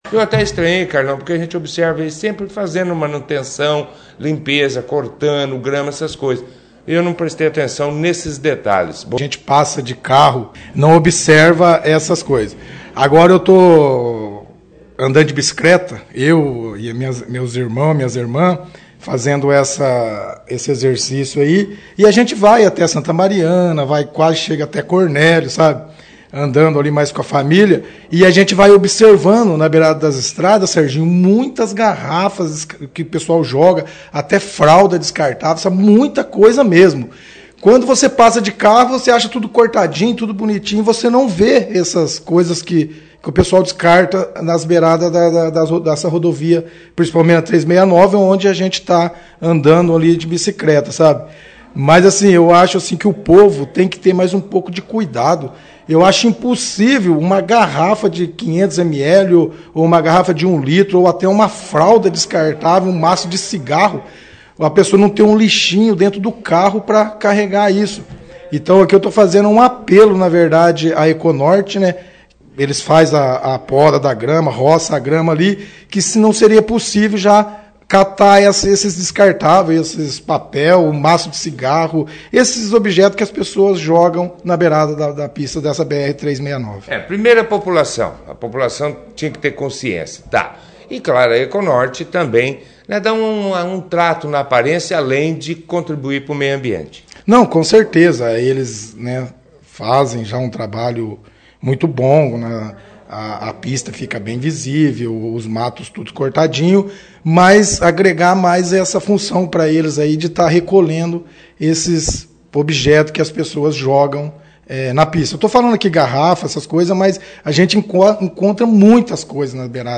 19ª sessão ordinária de 2020, do legislativo bandeirantense